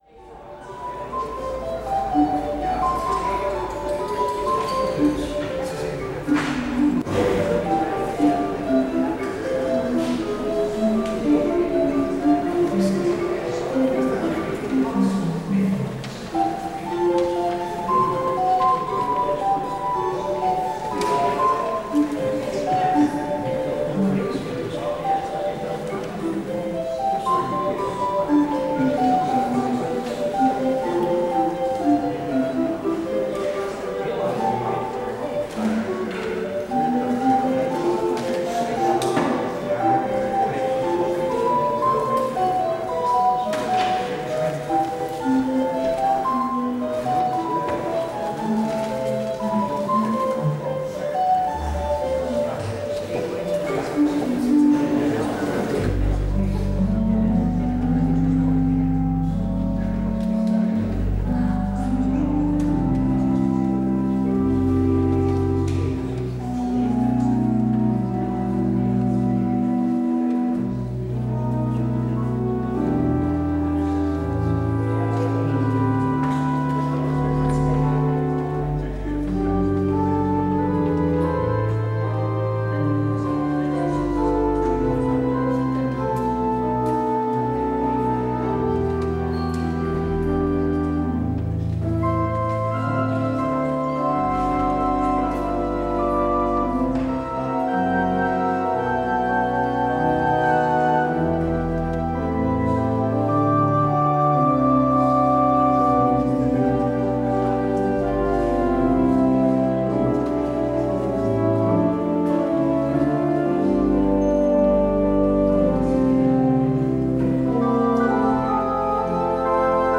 Het openingslied is: Psalm 100.